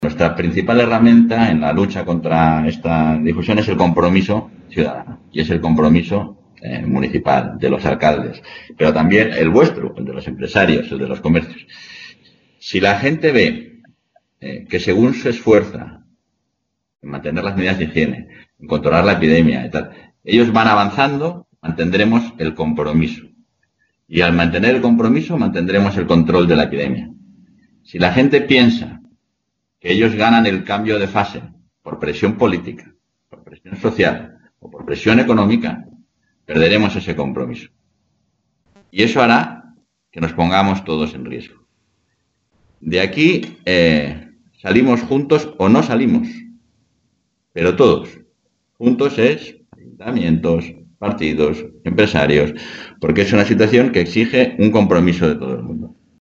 Audio vicepresidente de la Junta.